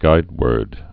(gīdwûrd)